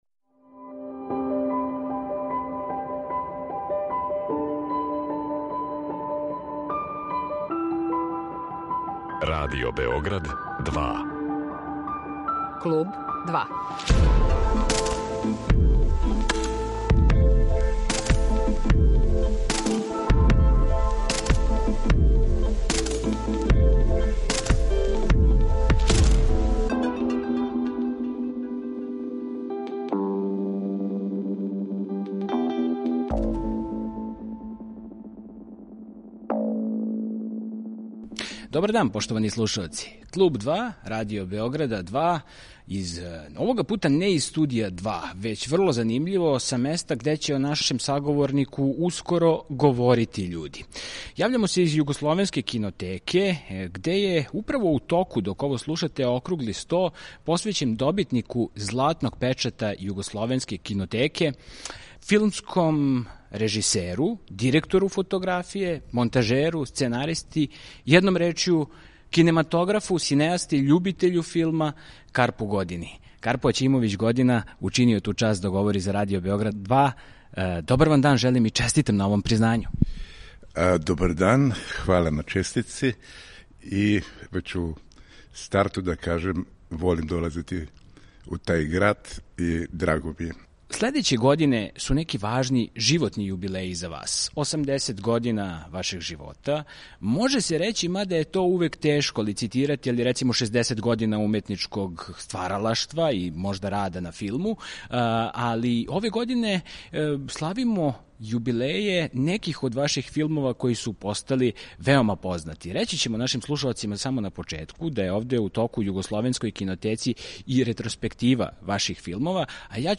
О својим почецима и аутентичном филмском језику, о кино-клубовима, аматерским и експерименталним остварењима, о сарадњи са Желимиром Жилником, Лорданом Зафрановићем, Бранком Вучићевићем, Батом Ченгићем, о брегу над Мошорином, о филмовима „Црвени буги", „Сплав медуза" и „Вештачки рај", о ликовности на филму, о кадровима који се налазе негде између фотографије и филма, о позоришту и радију и о још по нечему, Карпо Година говори за Радио Београд 2 на месту где о његовом стваралаштву говоре други...